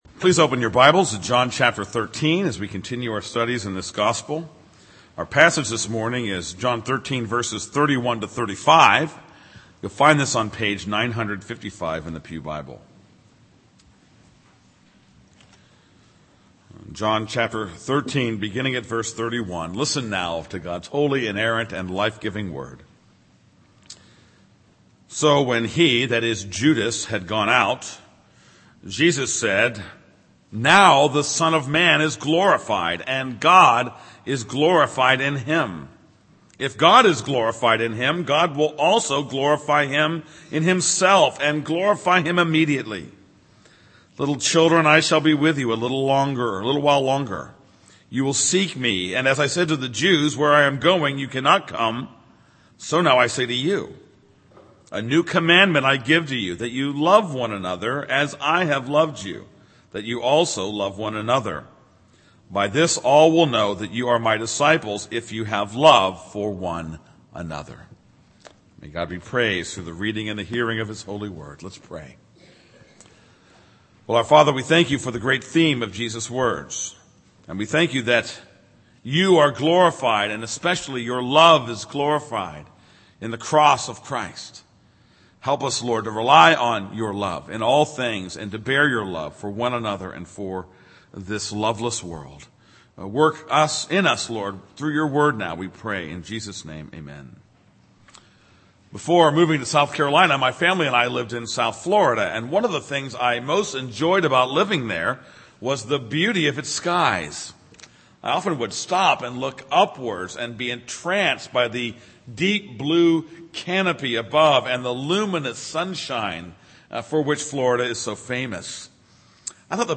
This is a sermon on John 13:31-35.